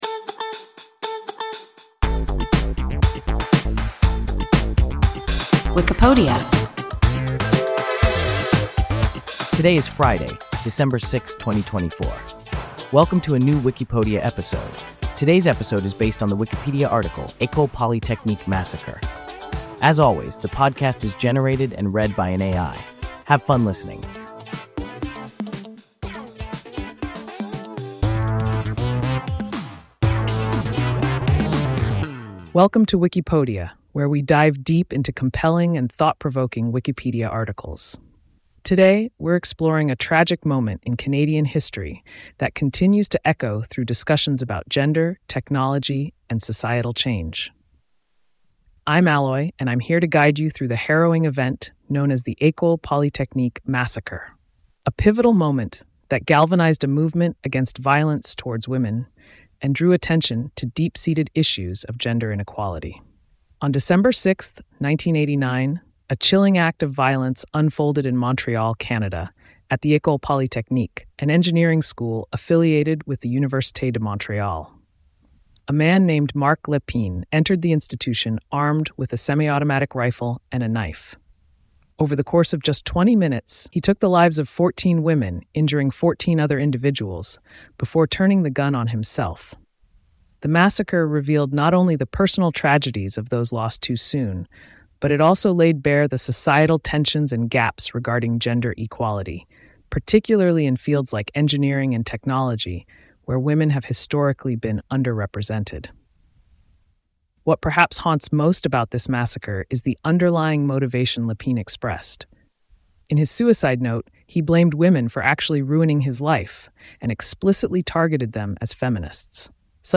École Polytechnique massacre – WIKIPODIA – ein KI Podcast